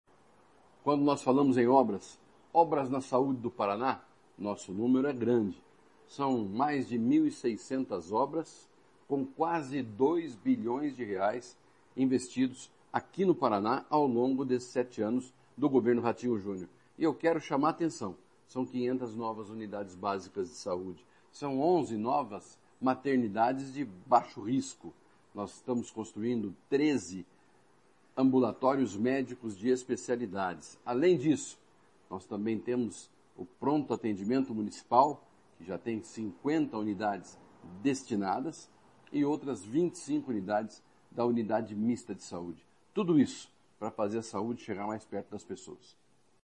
Sonora do secretário da Saúde, Beto Preto, sobre a regionalização da saúde no Paraná